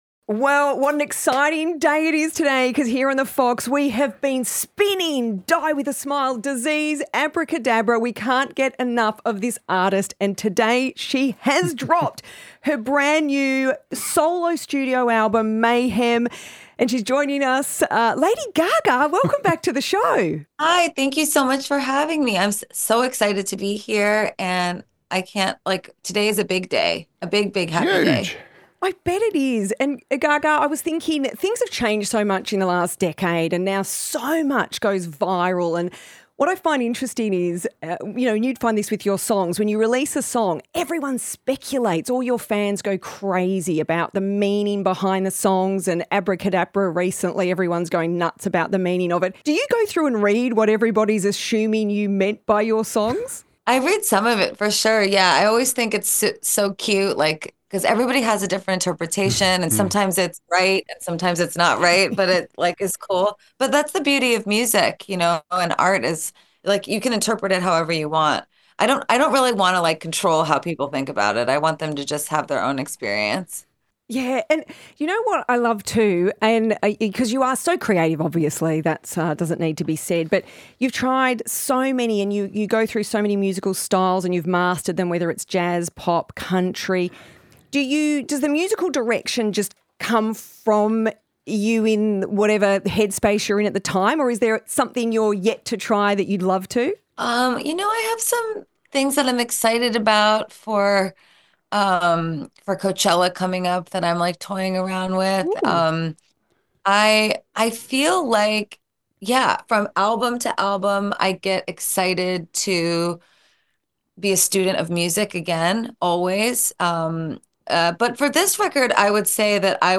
Gaga teased a tour on The Fox’s Fifi, Fev & Nick radio show on Friday morning, saying, “I can’t formally announce anything, but I can tell you that I’m going to be announcing… some things.”
The-Foxs-Fifi-Fev-Nick-Lady-Gaga-Interview.mp3